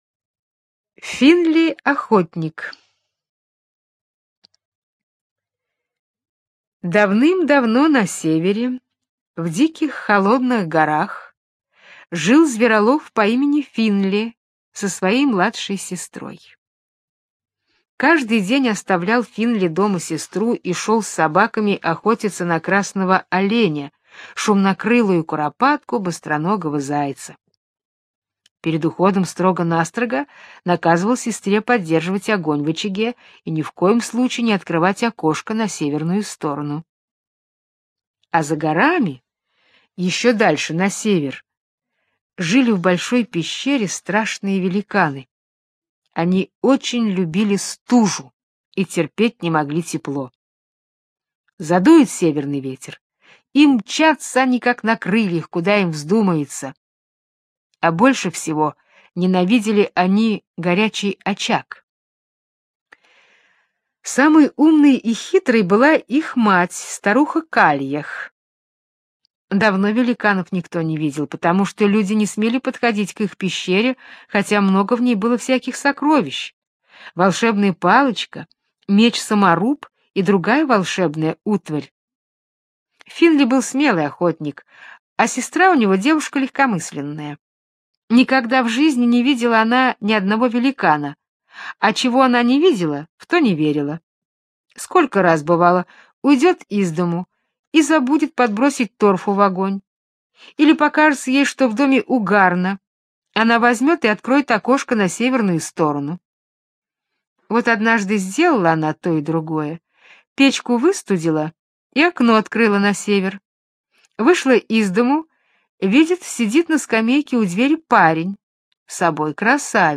Финли-охотник - британская аудиосказка - слушать онлайн